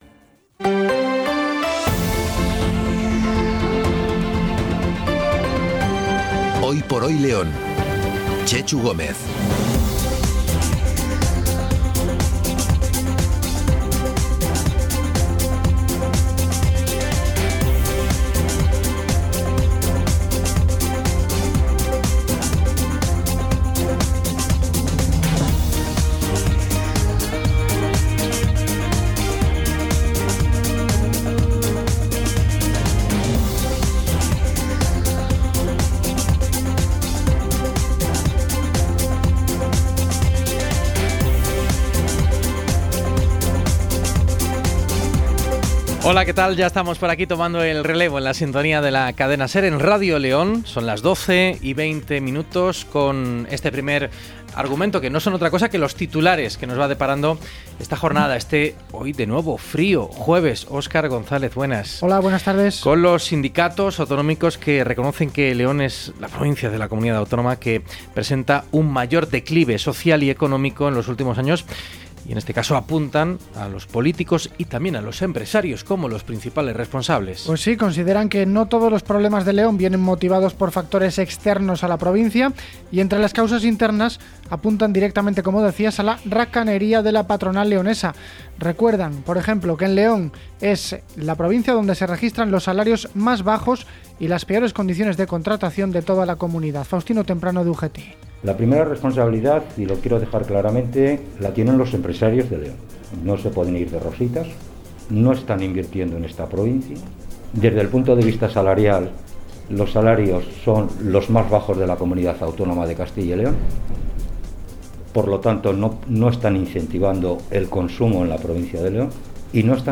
Os invitamos a escuchar el pequeño reportaje sobre la arquitectura del cole, un bosquejo de las características de nuestro edificio